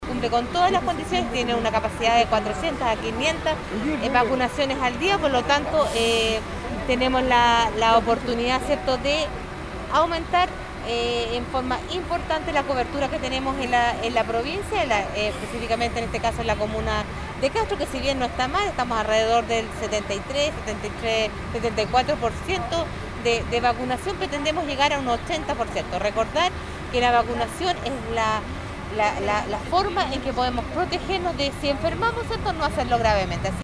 El bus dispuesto para recibir a la población durante esta semana está dispuesto en la plaza de la capital provincial, con capacidad para realizar aproximadamente unas 400 atenciones diariamente, expresó la seremi de Salud (S) Marcela Cárdenas.